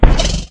Minecraft Version Minecraft Version latest Latest Release | Latest Snapshot latest / assets / minecraft / sounds / mob / horse / zombie / hit3.ogg Compare With Compare With Latest Release | Latest Snapshot